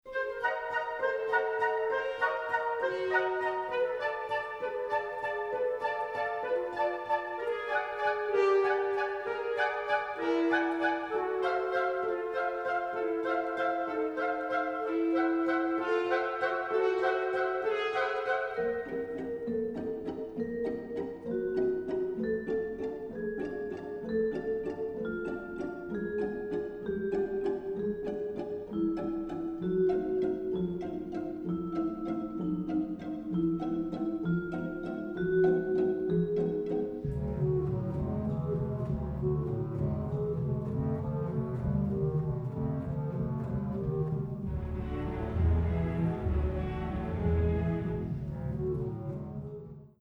Recorded on the OSE orchestra stage in September 2018